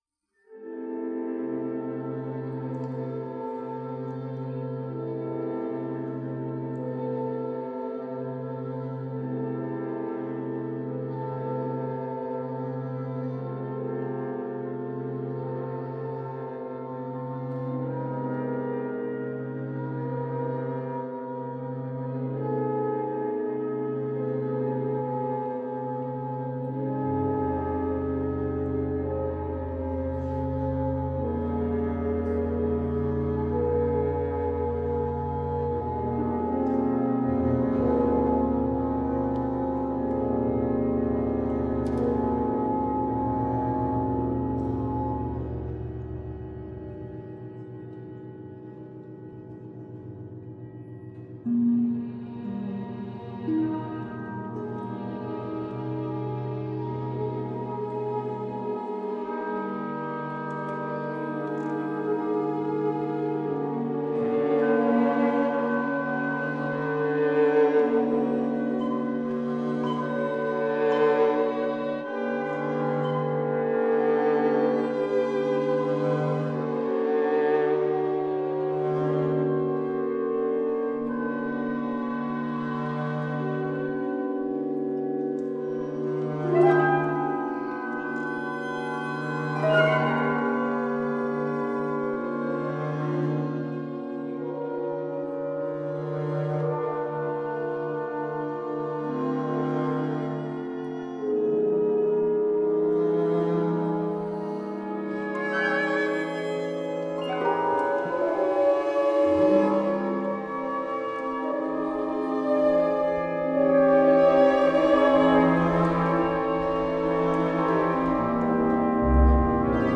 Il terzo dei 5 pezzi per orchestra op. 16, composti nel 1909.
In questi pezzi l’ambientazione espressionista e il linguaggio atonale che la sostiene raggiungono i livelli più alti.
Farben inizia con un accordo di cinque note (do, sol#, si, mi, la) lungamente tenuto che si alterna fra 2 gruppi strumentali: 2 flauti, clarinetto, fagotto, viola, l’uno e corno inglese, tromba (sordina) fagotto corno (sordina), viola, l’altro, con il contrabbasso a fungere da legame.
Così dentro a Farben c’è anche un canone a 5 voci quasi impercettibile perché le melodie passano da uno strumento all’altro e la sua identificazione è resa anche più complessa da gruppetti di suono puntuali (quasi polvere) di densità crescente.
Qui la percezione è di una apparente staticità iniziale che si frammenta, via via, in una molteplicità di voci fino al ricongiungimentol finale in un cangiante tessuto sonoro.
È un brano breve: soltanto 44 battute che, inserite nel bel mezzo del furore espressionista dell’op. 16, suonano come un intermezzo meditativo.